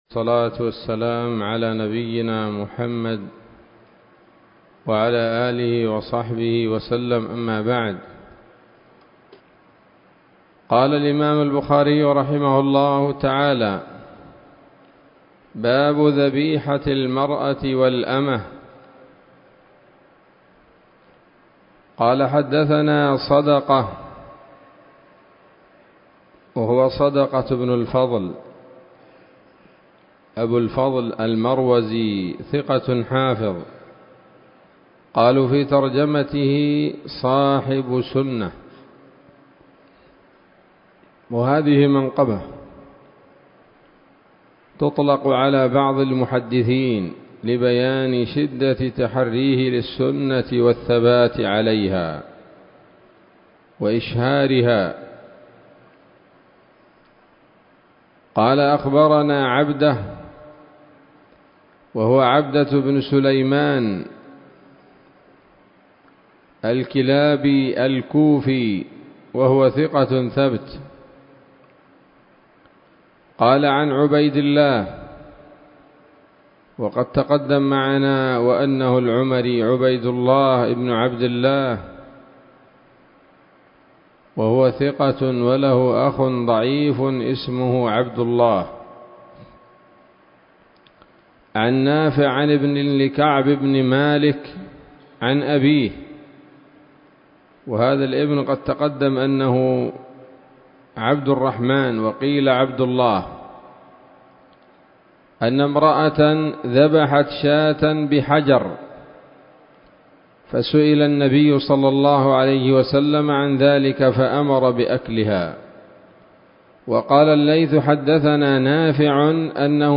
الدرس السادس عشر من كتاب الذبائح والصيد من صحيح الإمام البخاري